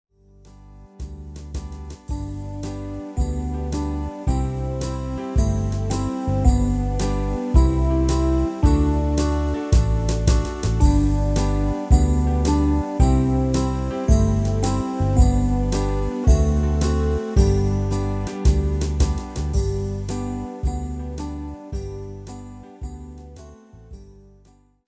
slovenské koledy v ľahkej úprave pre klavír